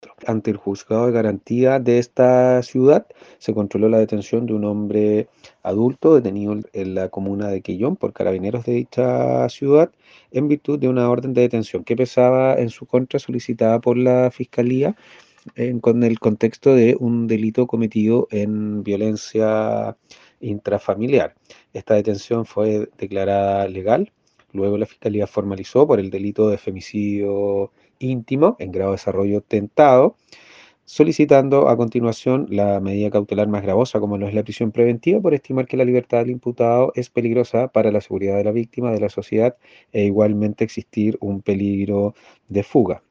Así se informó desde la fiscalía de Castro, donde con ocasión de una denuncia efectuada se logró la detención de este hombre, quien cometió estos repudiables actos en contexto de violencia intra familiar, señaló el fiscal Fernando Metzner.